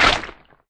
ground_break.ogg